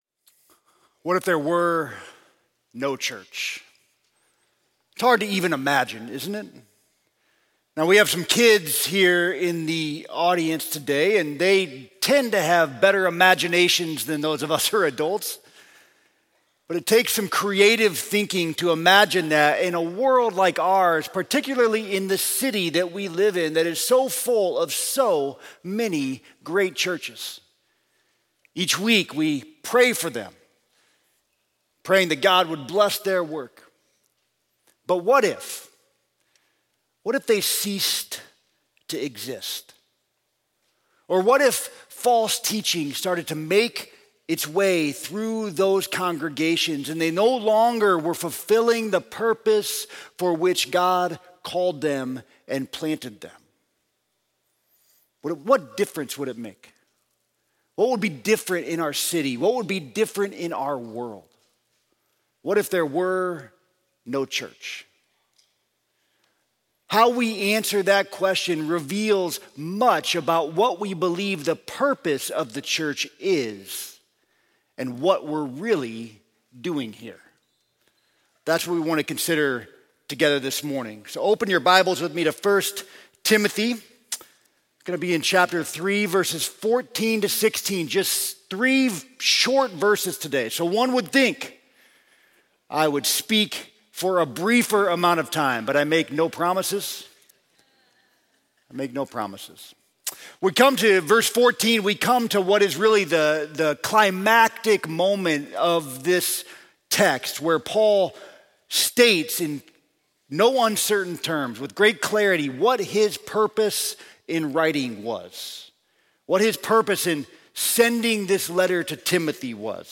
Sermon: The Pillar and Support of the Truth